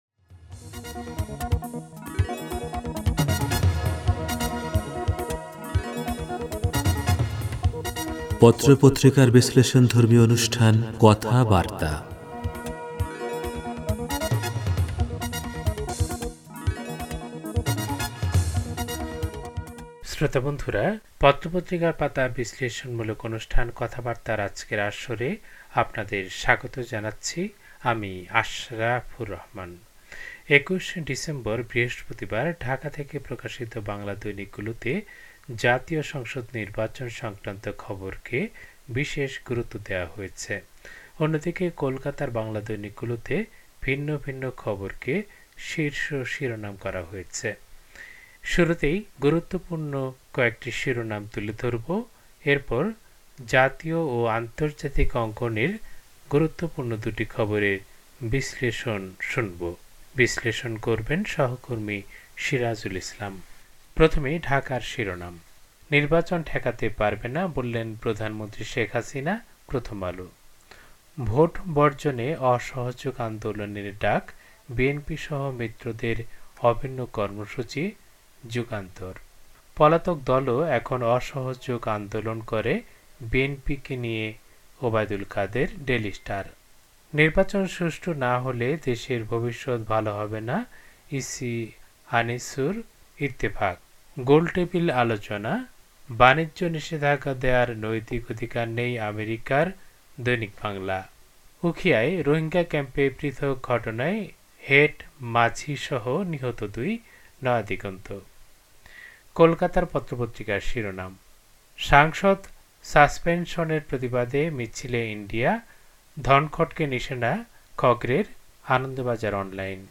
পত্রপত্রিকার পাতার অনুষ্ঠান